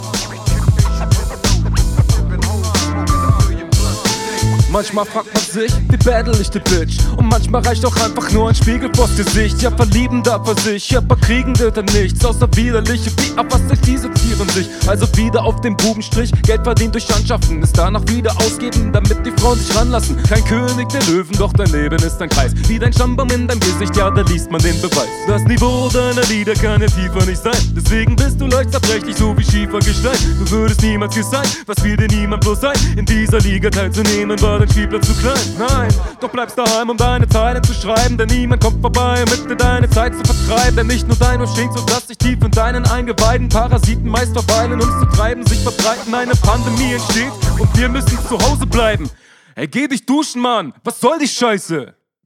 Auf dem Beat gefällst du mir richtig, das ist fast schon Jazz ;).
Soundquali auch wieder akzeptabel.
Nicer Beat, Stimme gefällt mir auch hier wieder richtig gut.